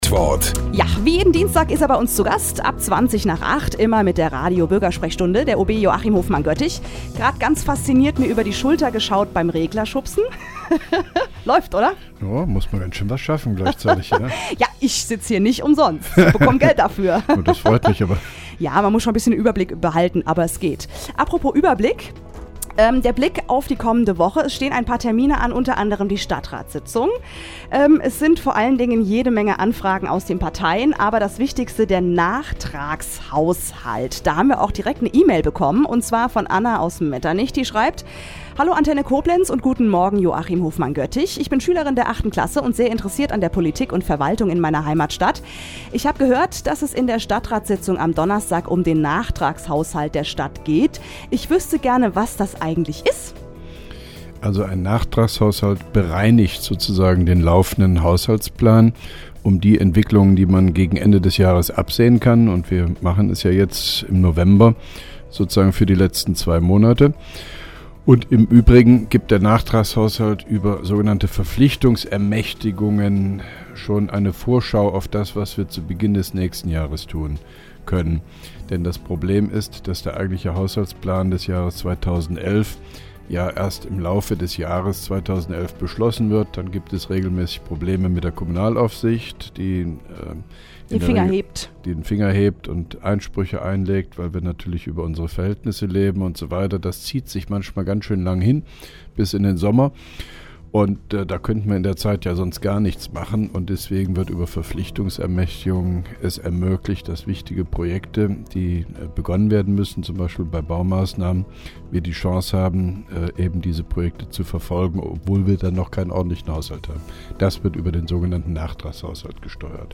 (2) Koblenzer OB Radio-Bürgersprechstunde 2.11.2010